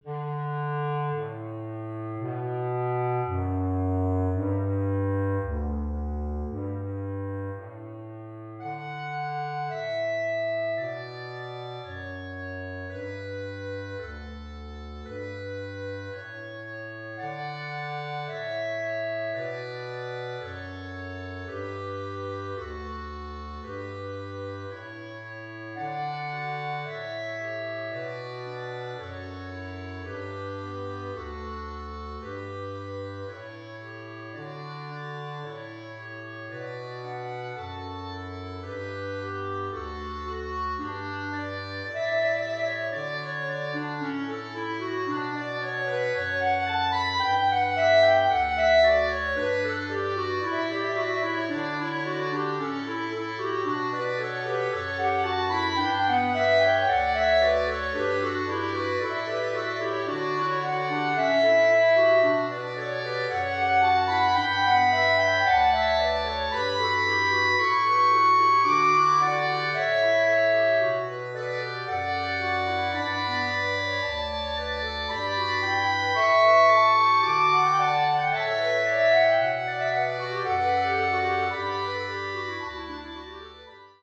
Klarinettenquartett